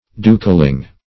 Dukeling \Duke"ling\, n. A little or insignificant duke.